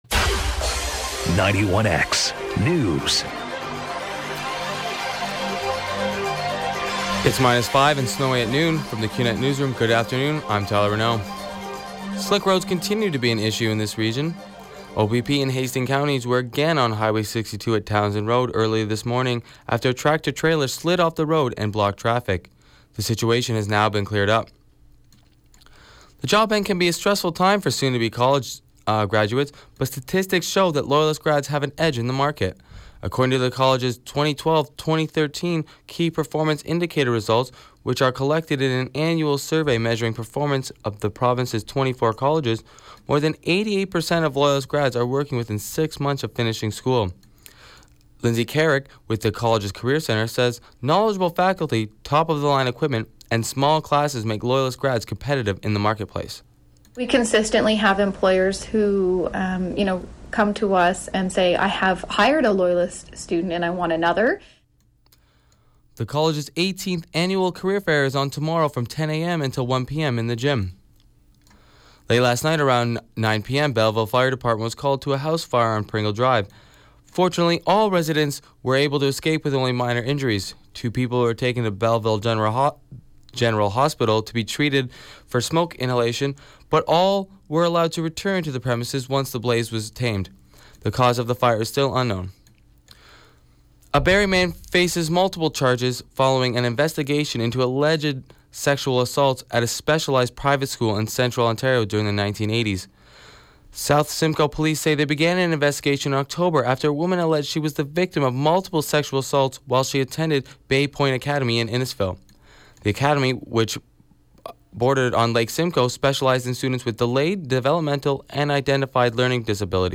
Wednesday, Feb. 4 91X newscast